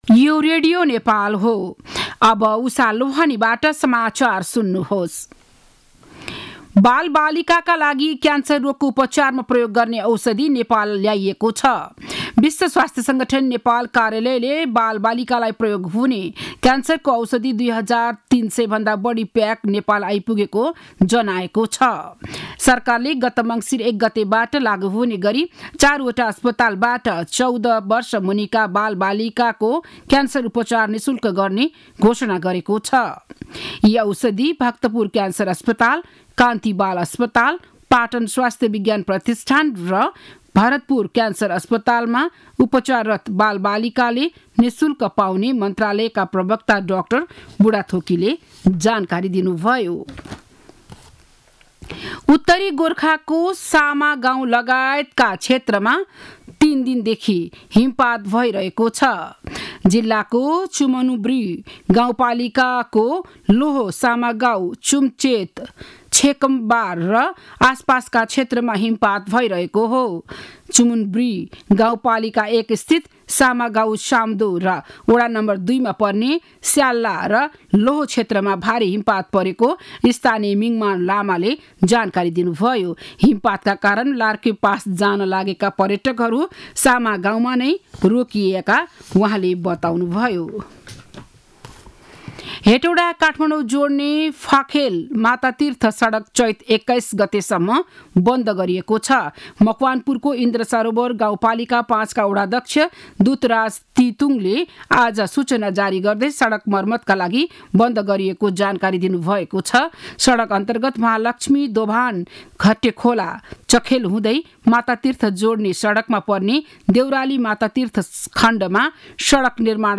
बिहान ११ बजेको नेपाली समाचार : ६ फागुन , २०८१
11-am-news-5.mp3